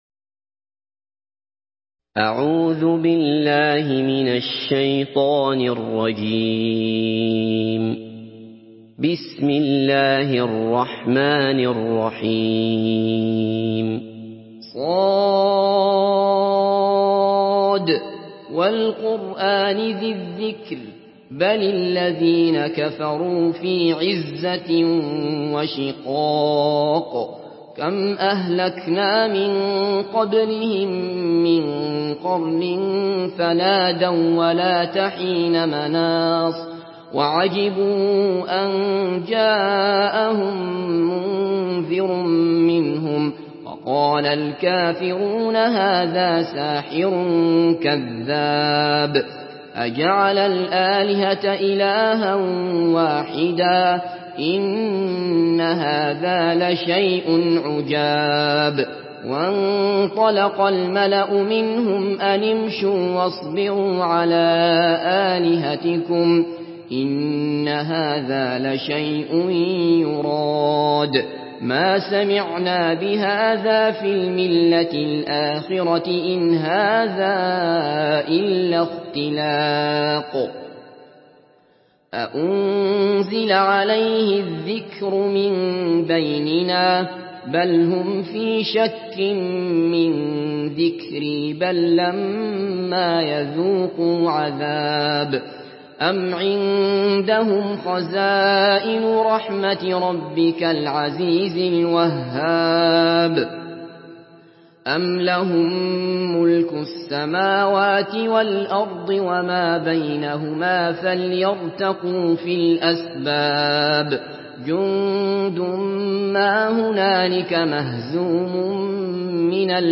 Surah Sad MP3 by Abdullah Basfar in Hafs An Asim narration.
Murattal